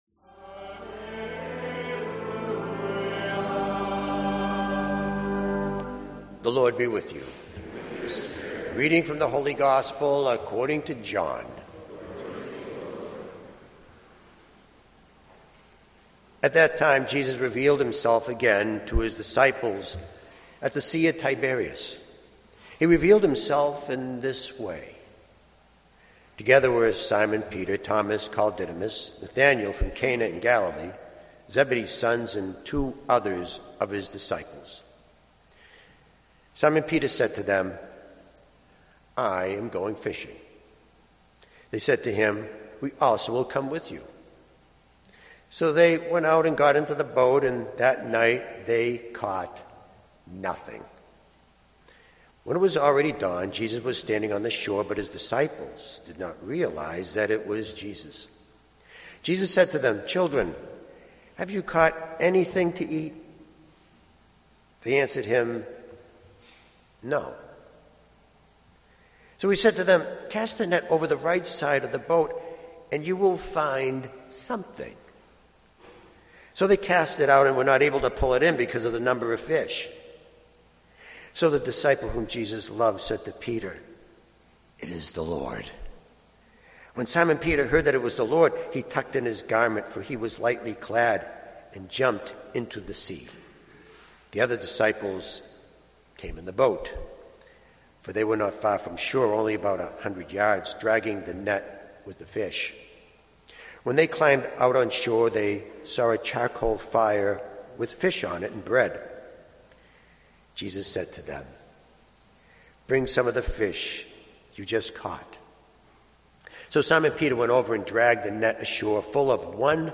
Audio recorded at Saint Clement Shrine, Boston, MA USA